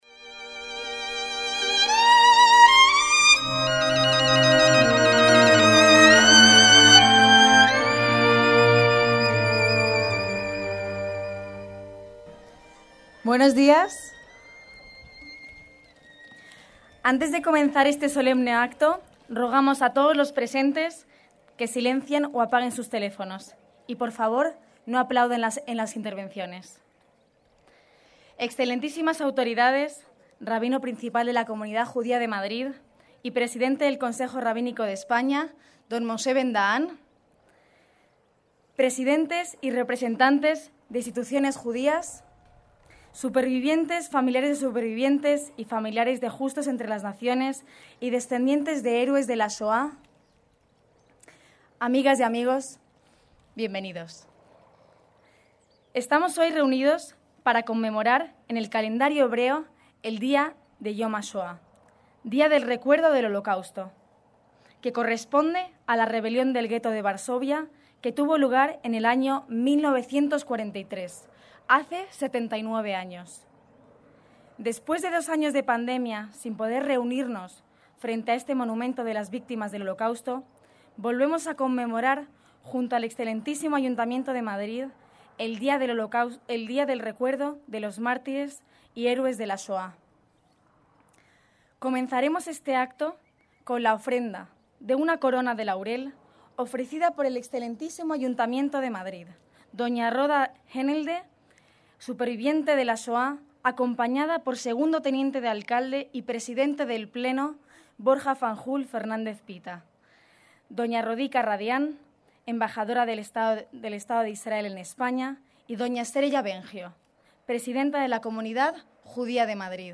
ACTOS EN DIRECTO